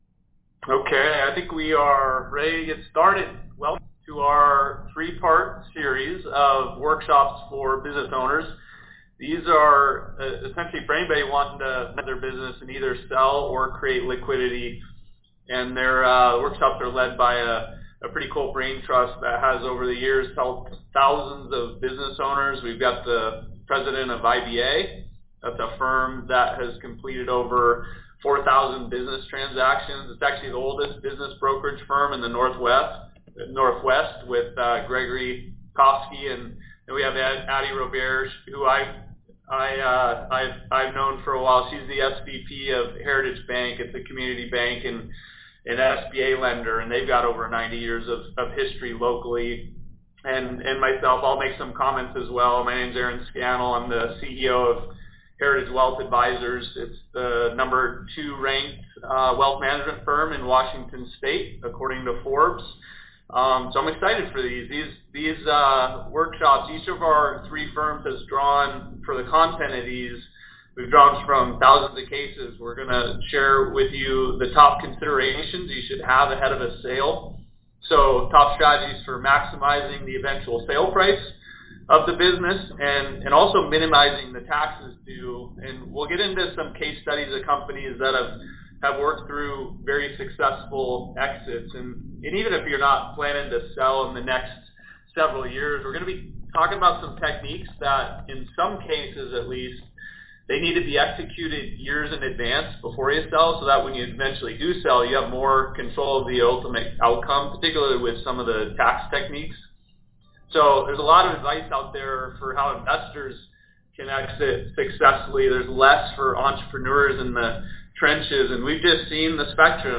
The audio file of that discussion is available to listen to for those who missed the first webinar.